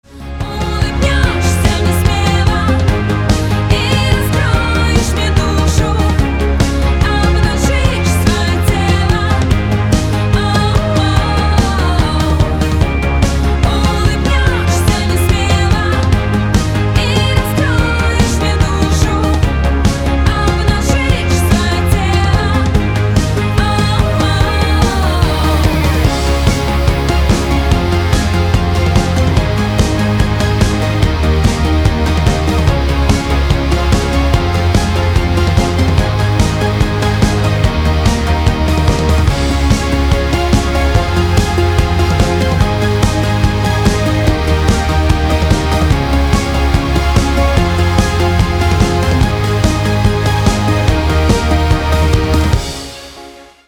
• Качество: 256, Stereo
поп
женский вокал
dance